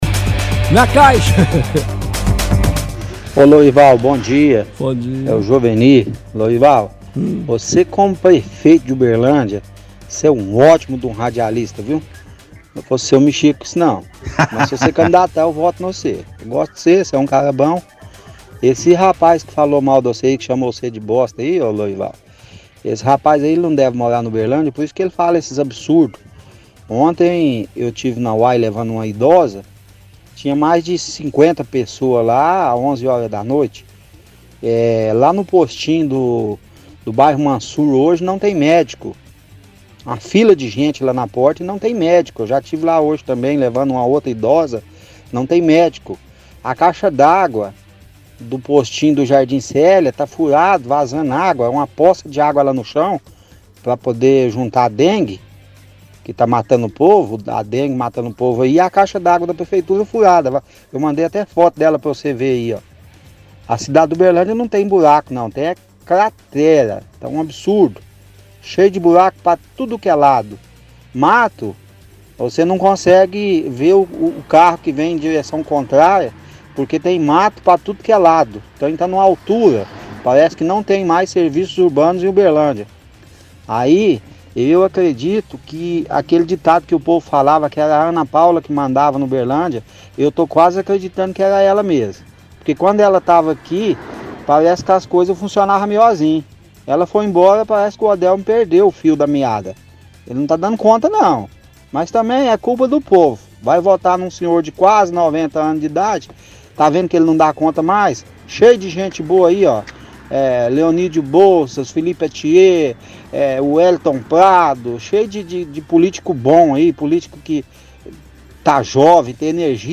– Ouvinte relata que levou passageiros em UAIs ontem e diz que “dá dó” e “está desumano o que estão fazendo”.
– Outro ouvinte relata que levou passageira em UAI e tinha 50 pessoas esperando.